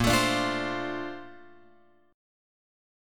A# Major 9th